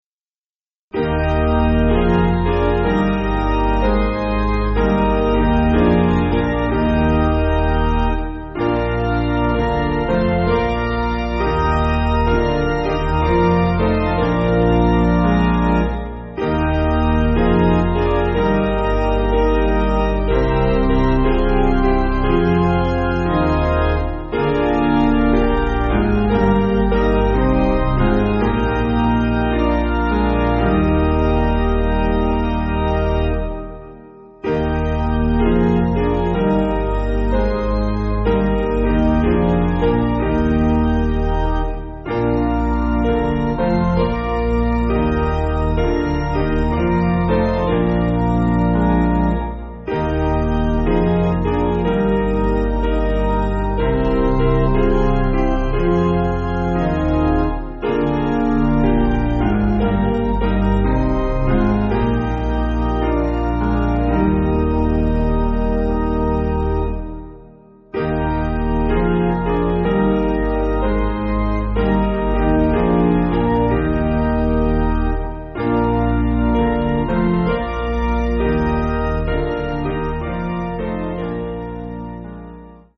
Basic Piano & Organ
(CM)   8/Eb